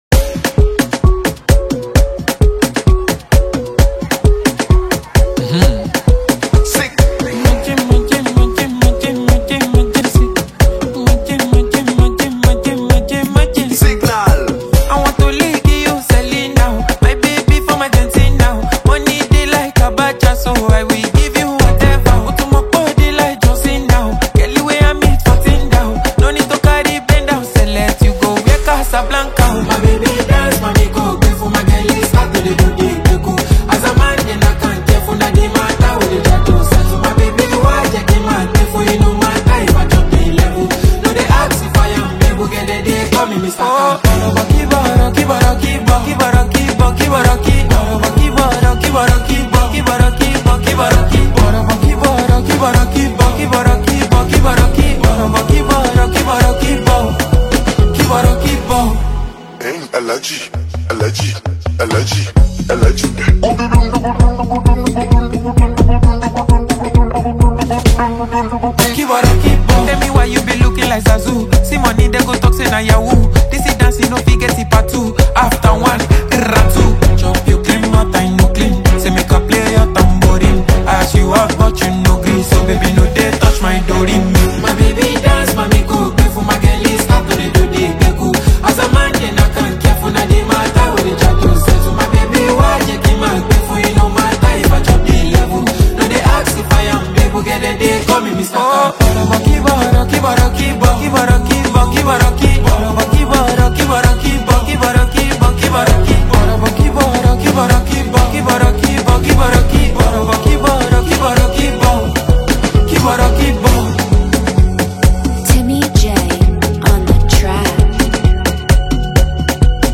infectious beats and catchy hooks
a world of rhythm and soul